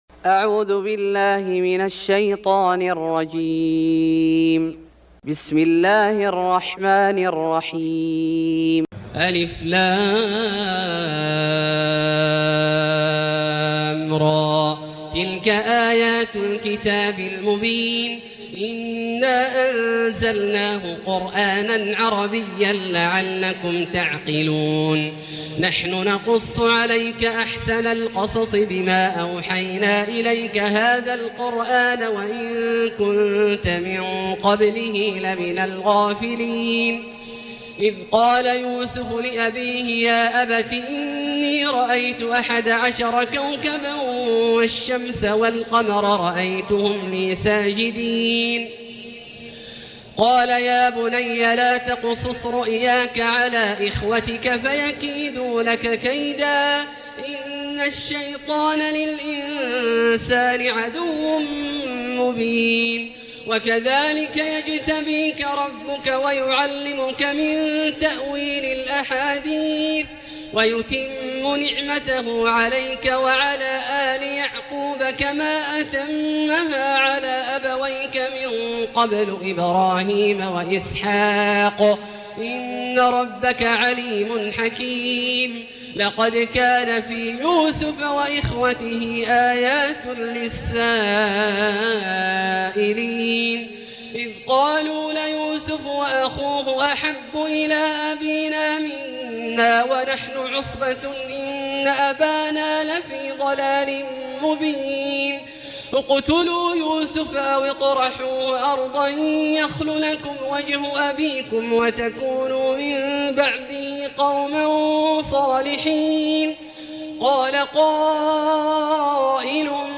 سورة يوسف Surat Yusuf > مصحف الشيخ عبدالله الجهني من الحرم المكي > المصحف - تلاوات الحرمين